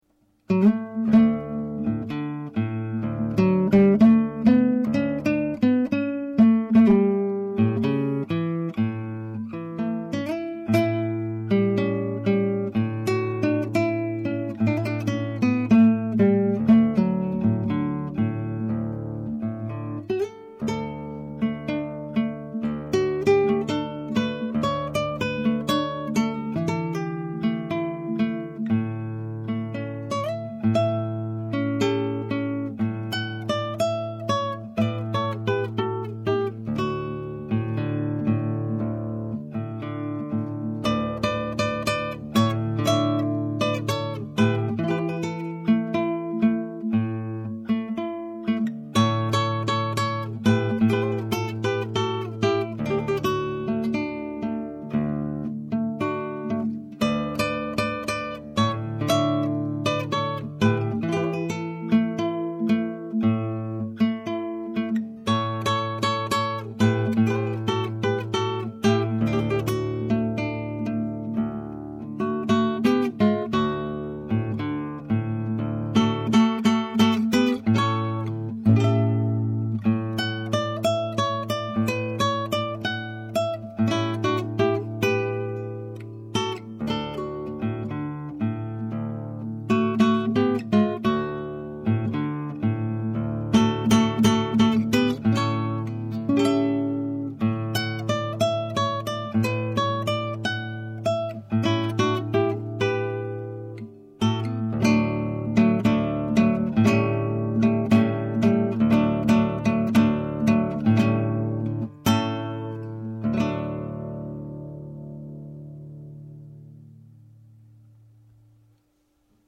Habanera
Het is een vrij langzame dans in 2/4 maat.  De sfeer is vaak melancholiek en de toonsoort is meestal mineur.
Je hoort een arrangement voor gitaar van Francisco Tárrega.